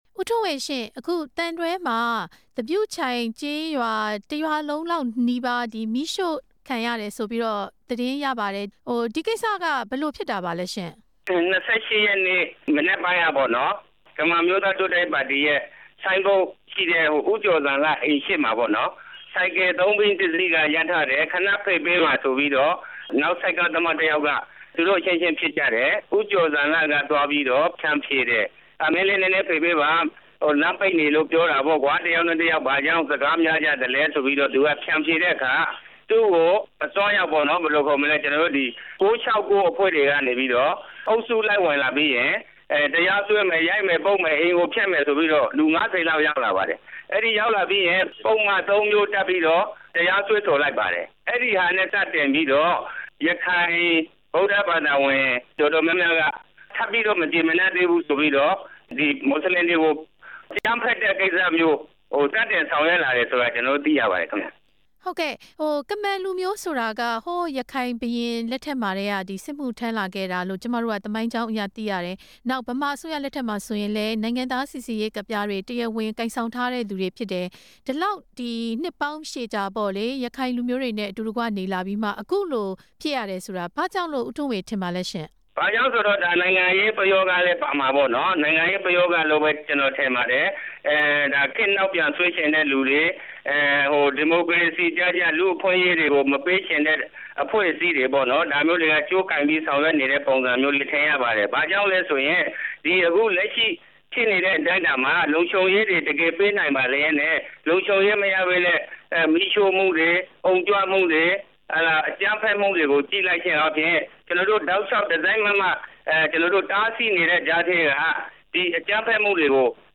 ကမန်တိုင်းရင်းသား ခေါင်းဆောင်တစ်ဦးနဲ့ ဆက်သွယ်မေးမြန်းချက်